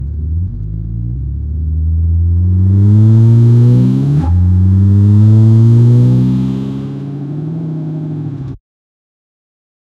a v8 turbocharged sports car going through gearshifts with straight cut gears
a-v8-turbocharged-sports--gqcwoprb.wav